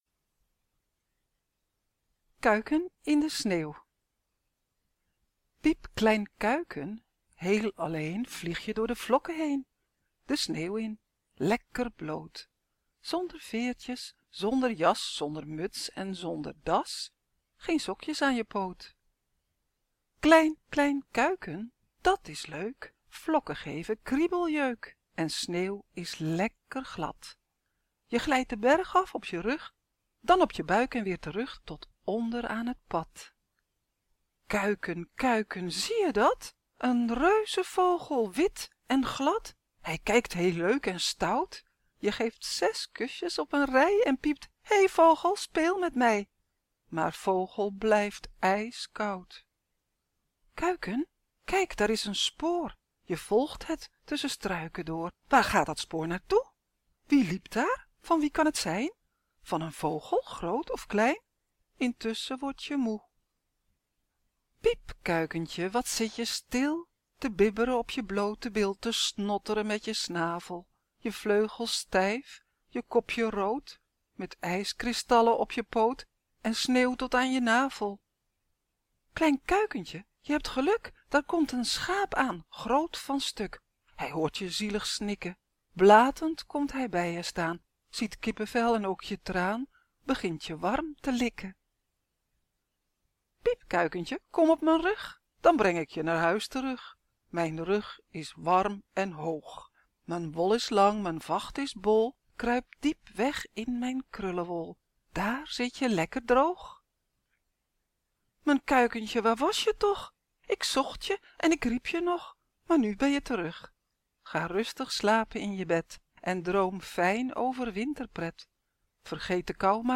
/ Verhalen / Door